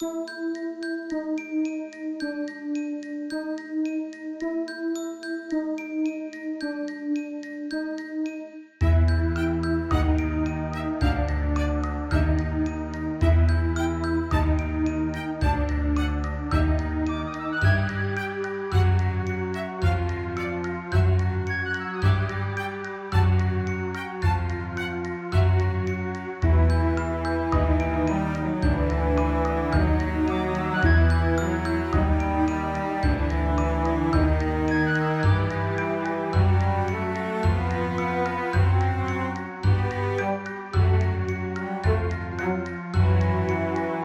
不安・迷子・ファンタジー系のアンビエントBGMです。
• Em / E♭解決しない進行で不安を演出
• 柔らかいパッド中心のアンビエント構成
• 風・揺れをイメージした淡いノイズレイヤー
• 主張しない、細い線のような旋律